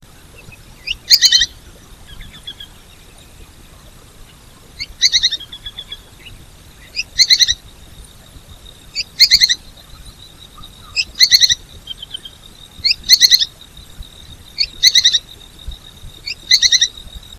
Synallaxis spixi - Pijuí común